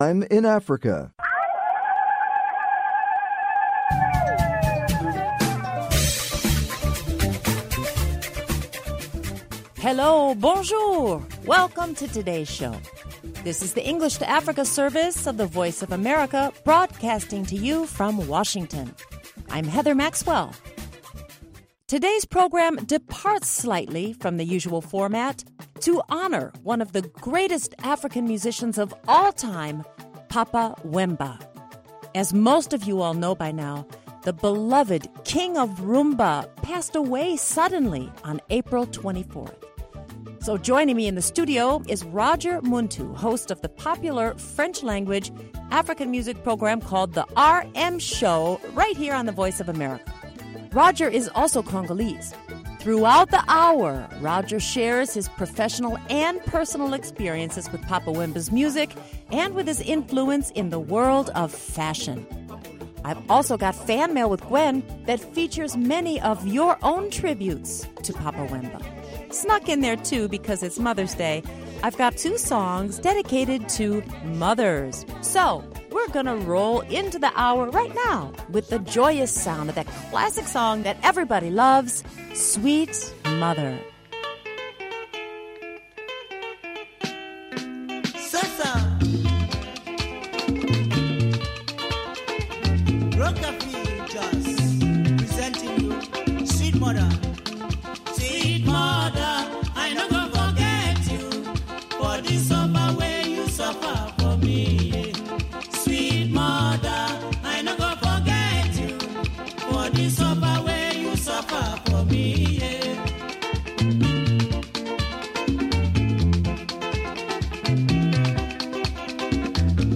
Music Time in Africa is VOA’s longest running English language program. Since 1965, this award-winning program has featured pan African music that spans all genres and generations.